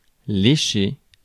Prononciation
Synonymes licher bouffer la chatte faire minette cirer les bottes cirer les pompes gougnotter faire la minette Prononciation France: IPA: [le.ʃe] Le mot recherché trouvé avec ces langues de source: français Traduction 1.